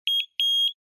Звук металлодетектора — 24 сек